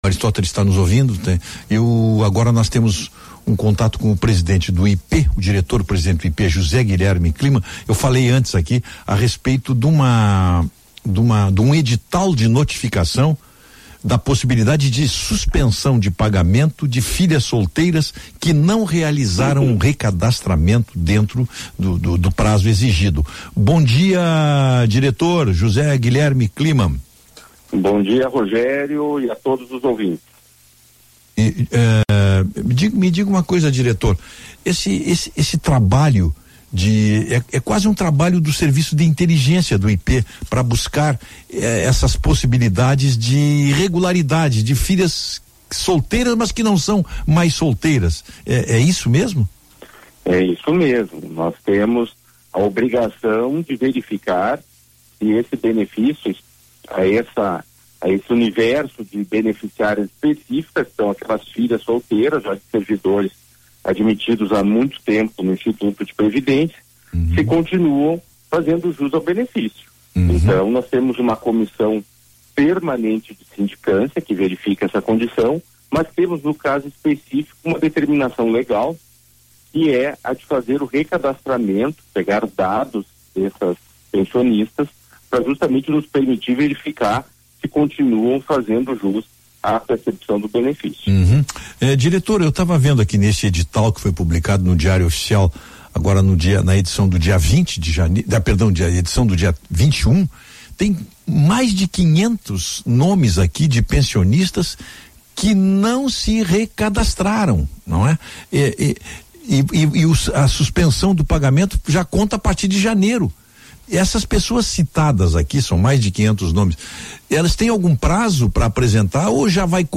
Entrevista do presidente do IPE Prev à rádio Bandeirantes